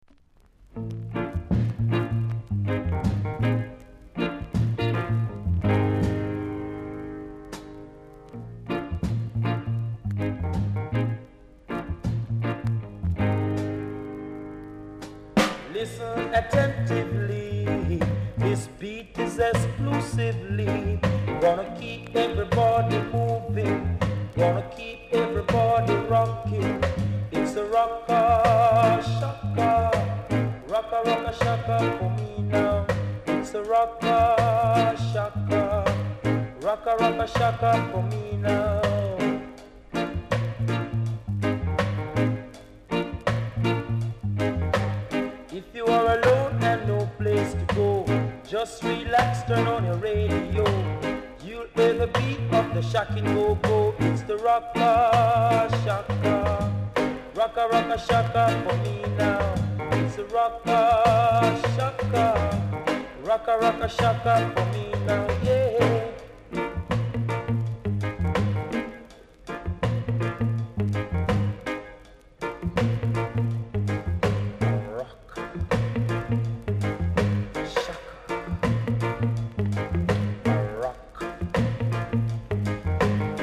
※多少ジリジリあります。ほか小さなチリノイズが少しあります。
コメント 両面KILLER ROCKSTEADY!!VERY RARE!!※レーベル両面ともダメージあり